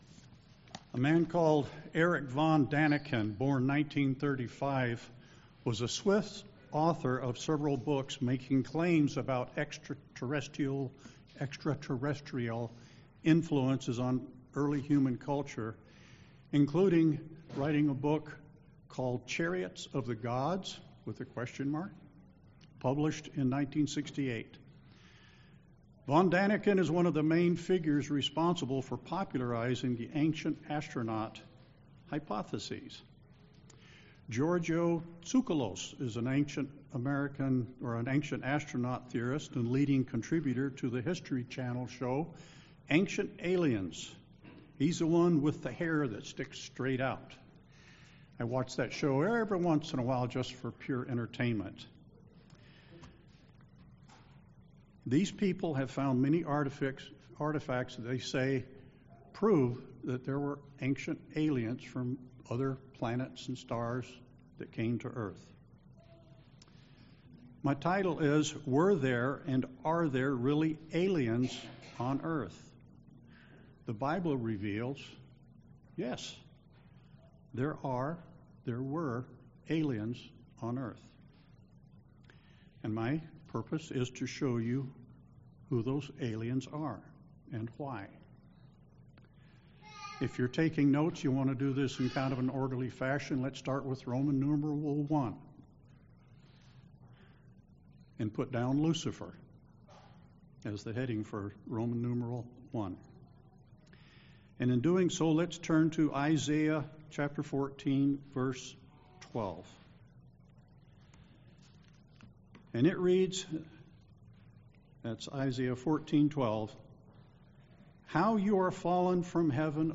Given in Milwaukee, WI
The Bible shows us who the real aliens are. aliens pilgrims strangers UCG Sermon Satan Lucifer Studying the bible?